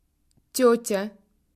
Consonnes
Écoutez la différence en russe sur ces quelques exemples:
"MOU"